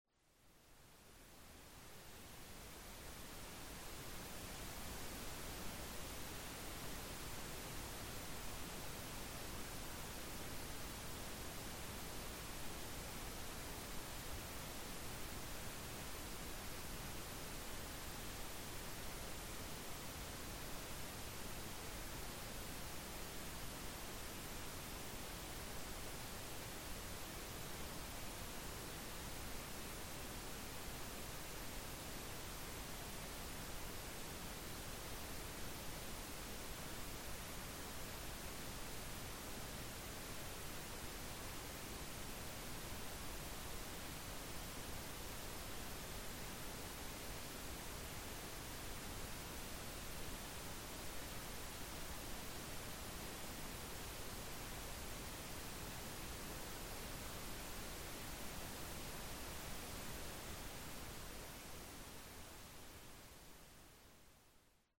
Calming nature recordings and ambient soundscapes.
Forest Morning — Bird Songs
Duration: 1:05 · Type: Nature Recording · 128kbps MP3
Forest_Morning_Birds.mp3